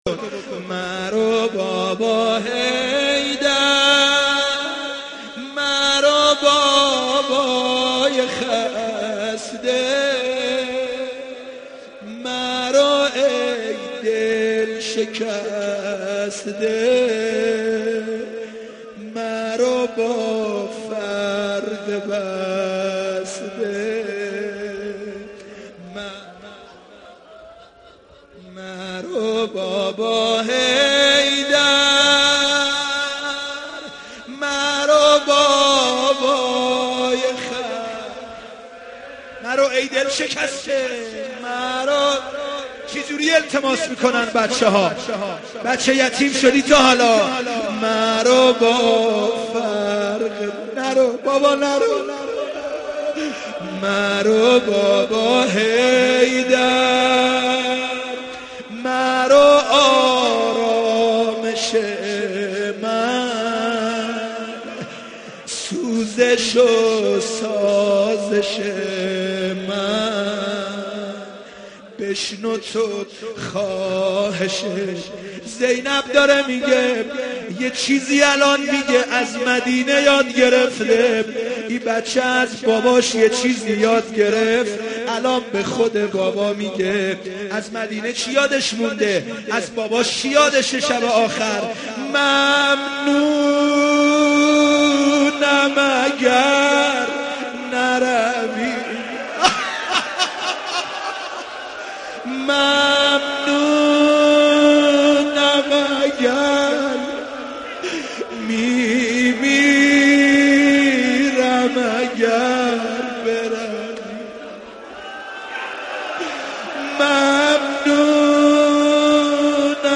رمضان 89 - روضه 2
رمضان-89---روضه-2